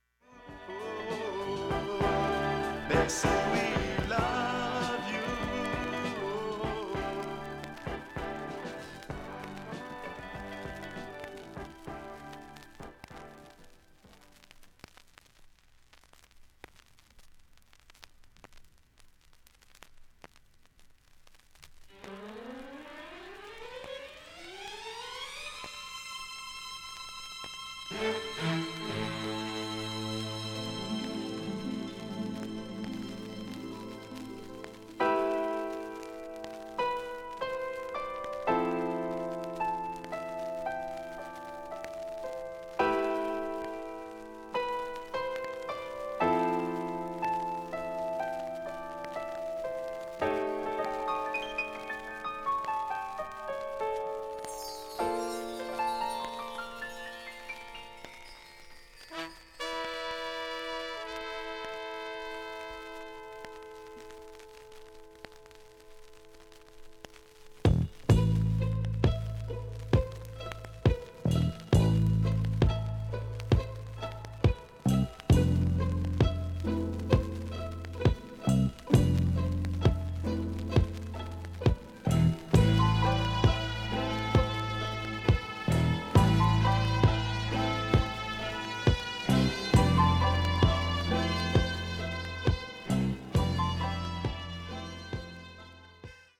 盤面きれいです音質良好全曲試聴済み。